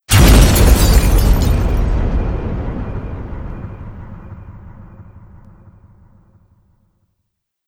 sunken.wav